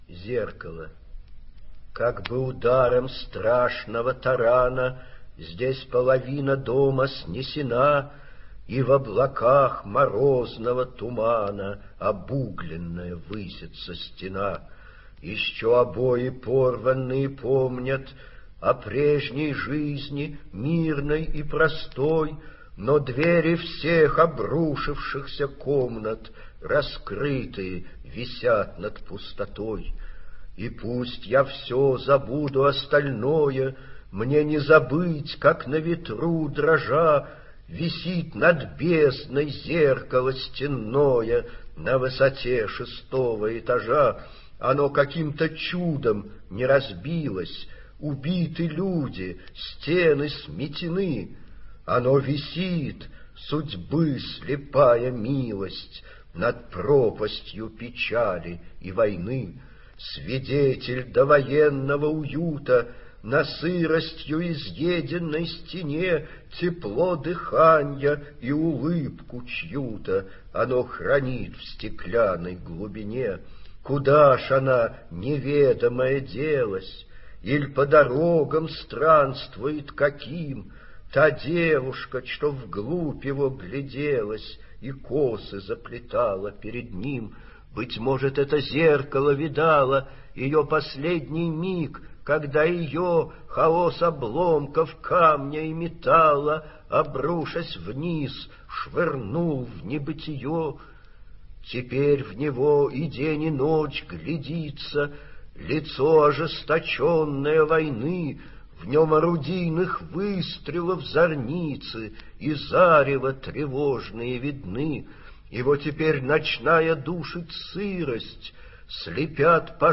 Вадим Шефнер – Зеркало (читает автор)
vadim-shefner-zerkalo-chitaet-avtor